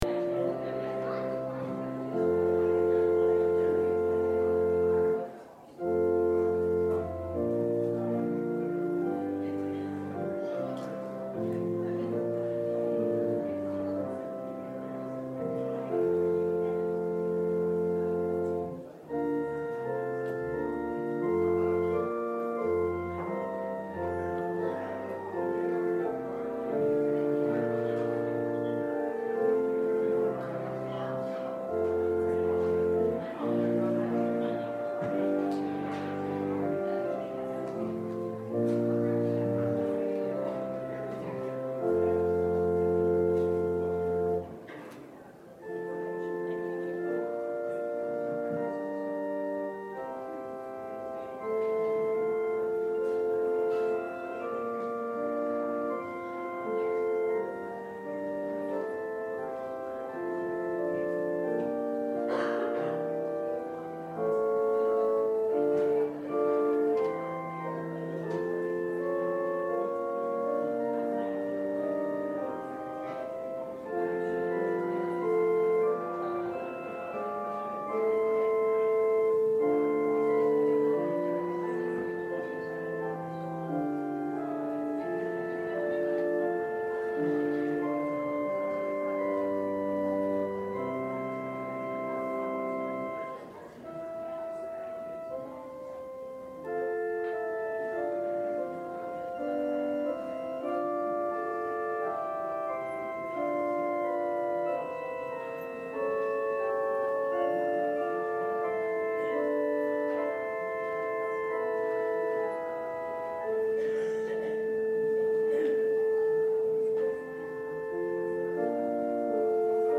Guest Speaker
Service Type: Sunday Worship